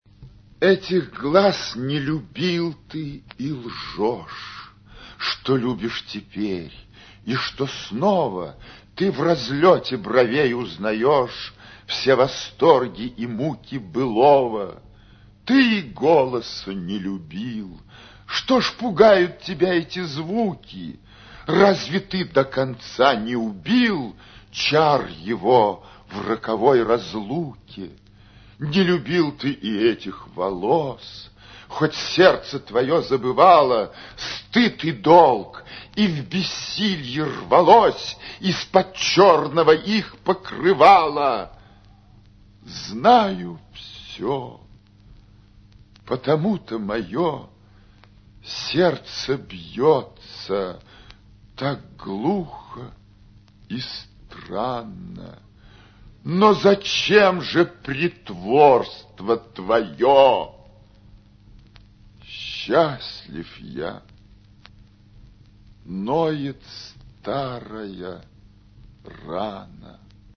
«Самая старая песня» — читает народный артист СССР Леонид Марков